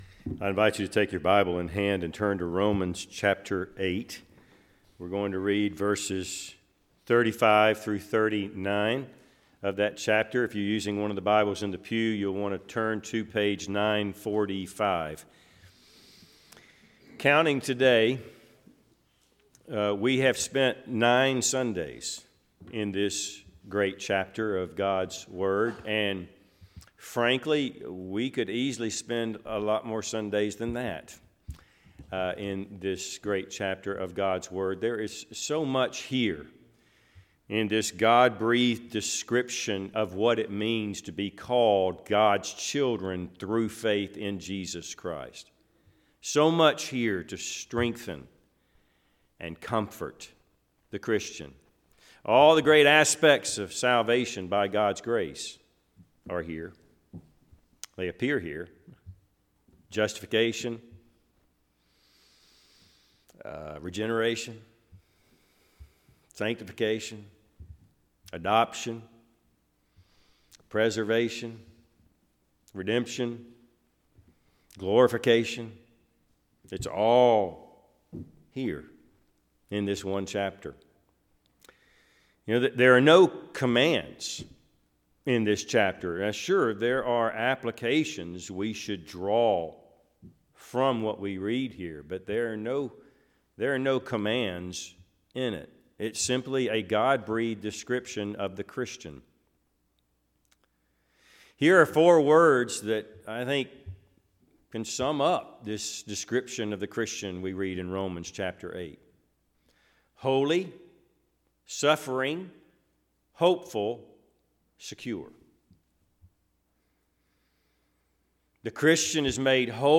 Service Type: Sunday AM Topics: God's love , perseverance , Security , Suffering